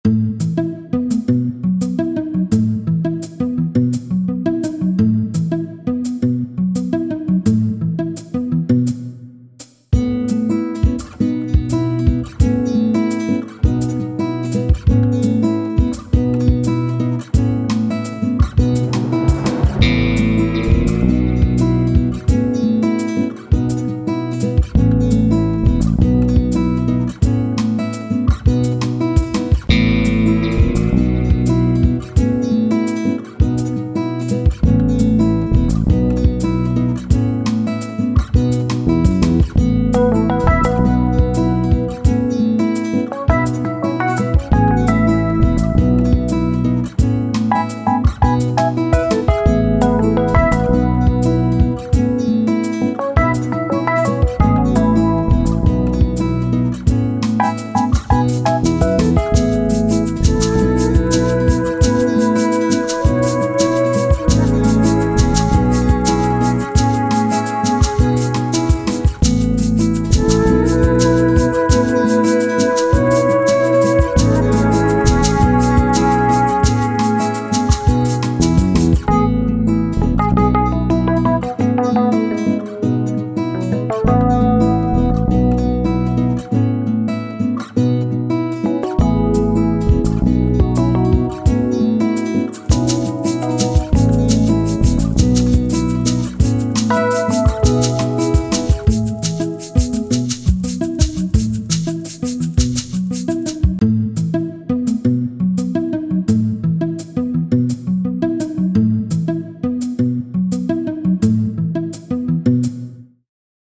windows-error-sound-effect-35894.mp3
Son erreur windows-error-sound-effect-35894.mp3